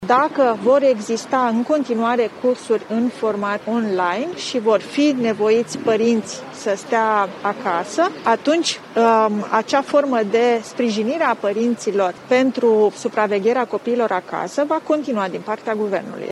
Ministrul Muncii, Raluca Turcan, a anunțat că părinții copiilor care rămân la școala online vor beneficia în continuare de ajutor de la stat pentru a putea sta cu ei.
02feb-14-Turcan-ajutor-parinti-scoala-copii.mp3